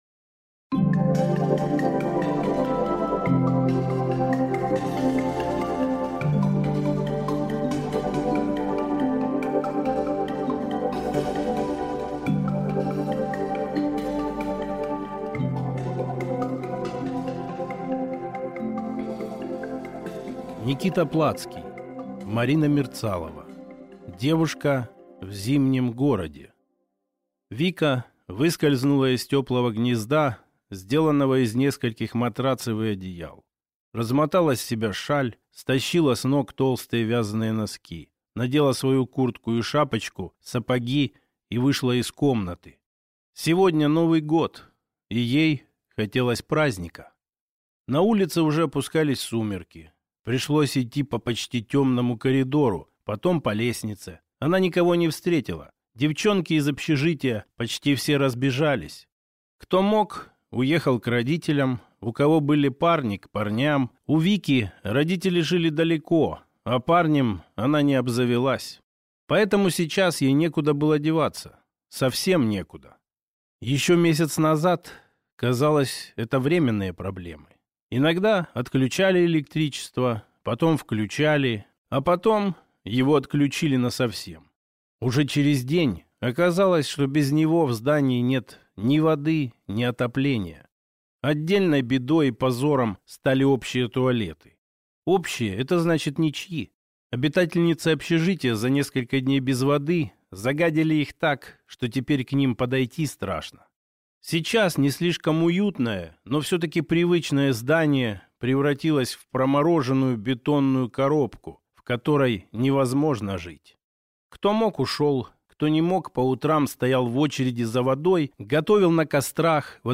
Аудиокнига Девушка в зимнем городе | Библиотека аудиокниг